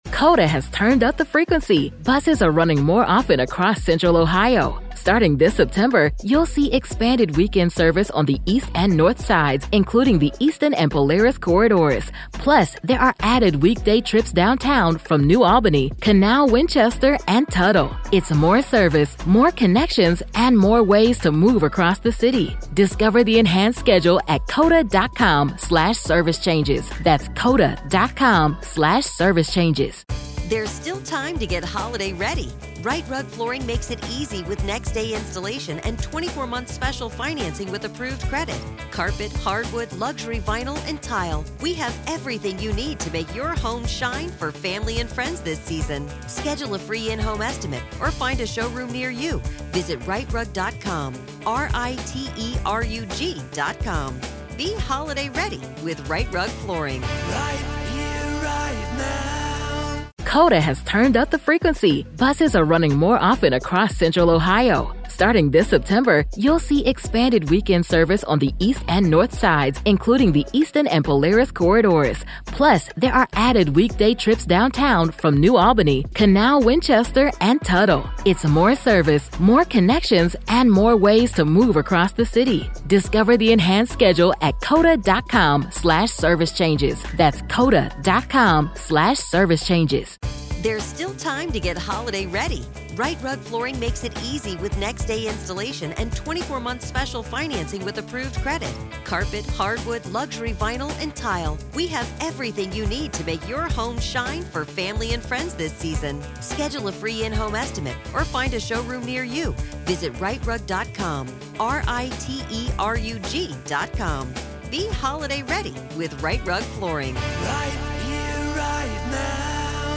Daily True Crime News & Breakdowns / WSU in the Hot Seat — Did They Ignore the Warnings About Kohberger?